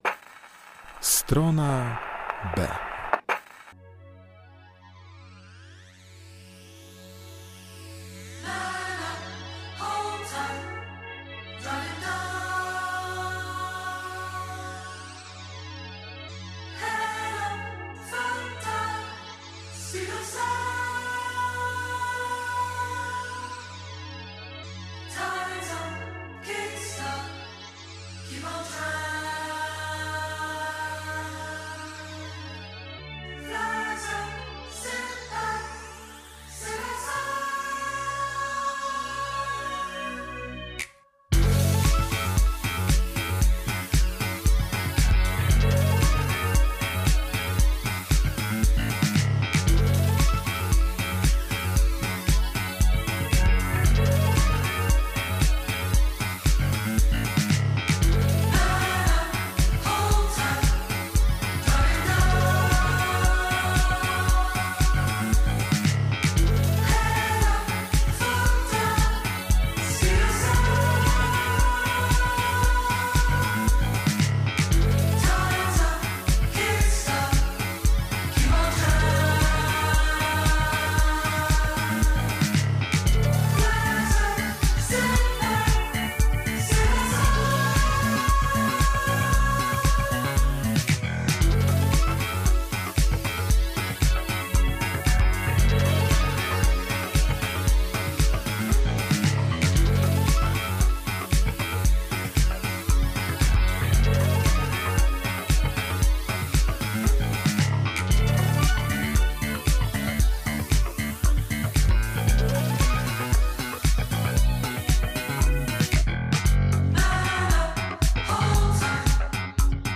STRONA B w elektronicznym stylu tym razem pod kierunkiem pań!